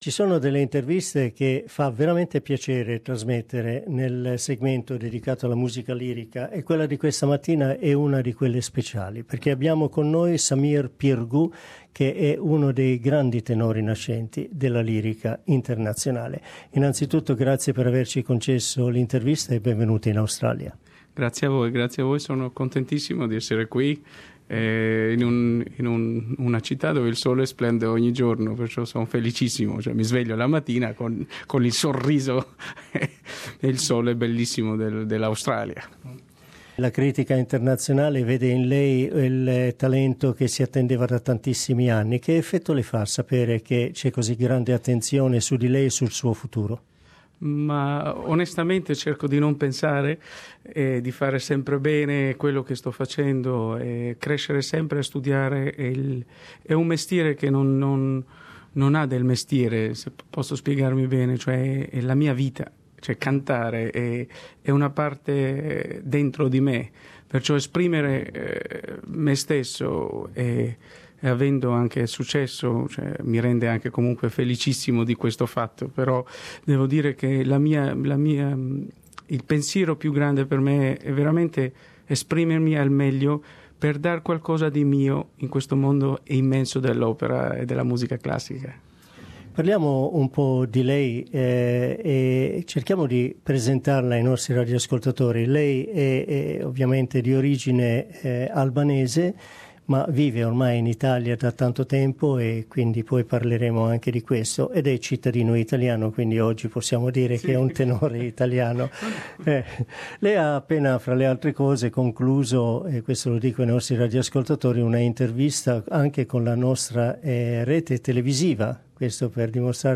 Il pastore è interpretato da Saimir Pirgu, del quale Luciano Pavarotti è stato un grande sostenitore. La nostra intervista con il tenore italo-albanese.